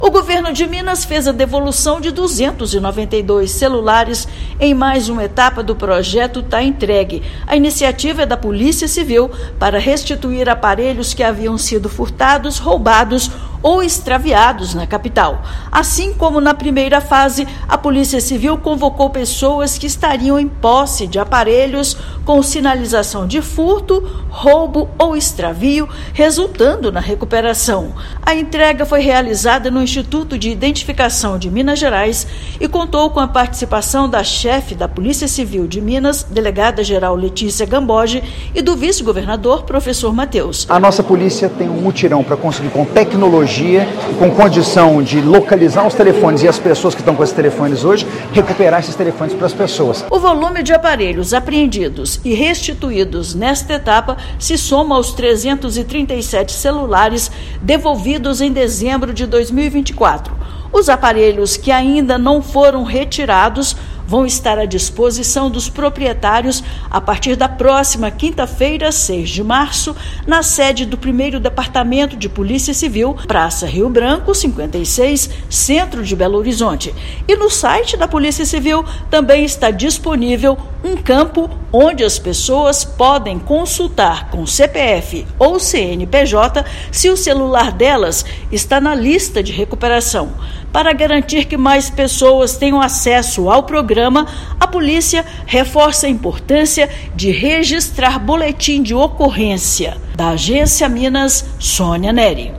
Proprietários foram convidados a comparecer ao Instituto de Identificação da Polícia Civil, em Belo Horizonte para a formalização da entrega dos aparelhos. Ouça matéria de rádio.